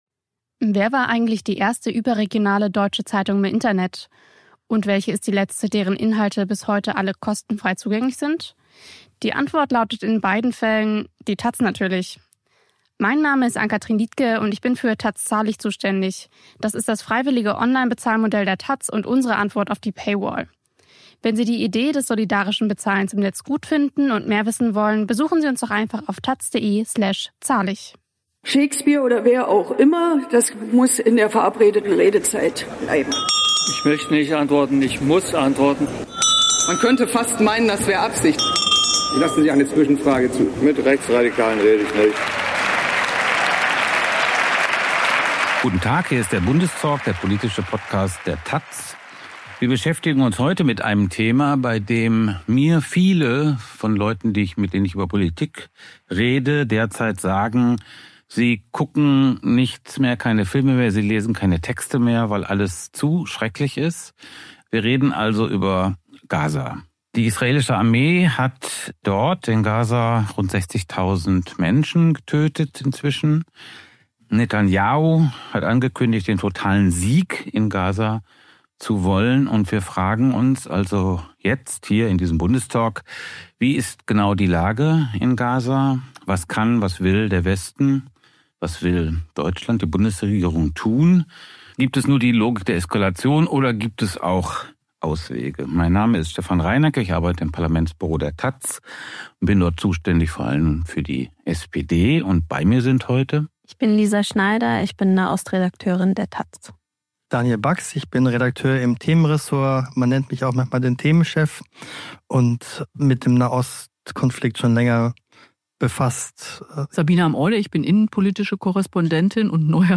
Einmal in der Woche blicken taz-Redakteur*innen auf die politische Lage im In- und Ausland